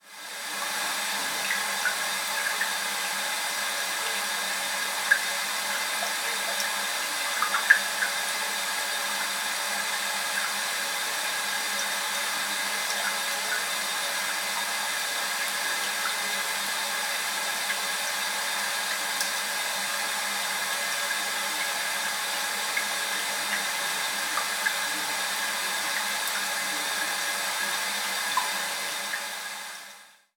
cisterna
goteo
Sonidos: Agua
Sonidos: Hogar